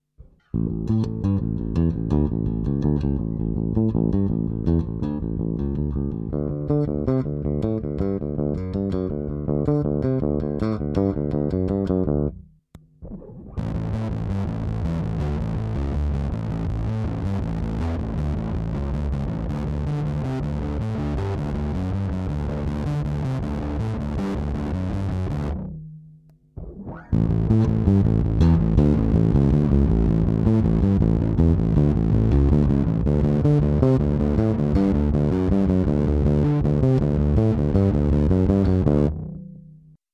Fuzz pedals allow bass players to reach new sonic territories with near-infinite sustain and an almost complete lack of dynamics, which gives a brutal sound not unlike an analogue synthesizer.
The following sample is an aggressive bass riff in the style of Muse—played first clean, then with fuzz, and finally with fuzz blended with clean signal.
Fuzz Muse Style
bt7_Fuzz_Muse_Style.mp3